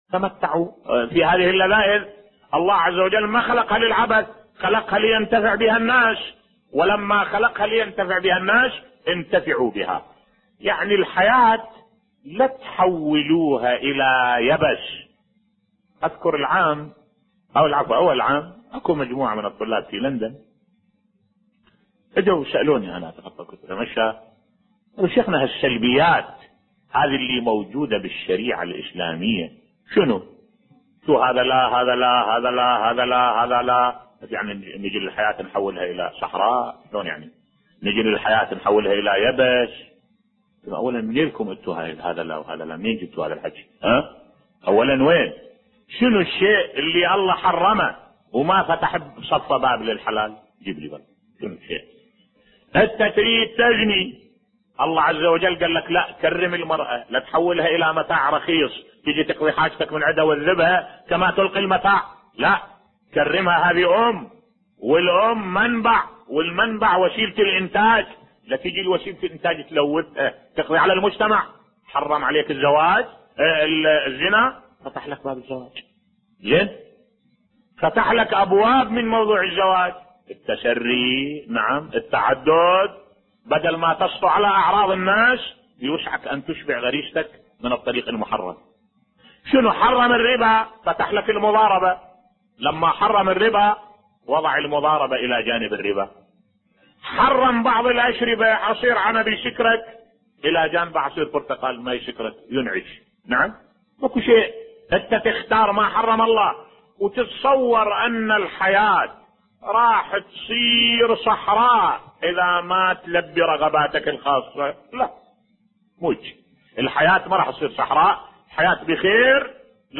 ملف صوتی جواب لبعض الطلاب بصوت الشيخ الدكتور أحمد الوائلي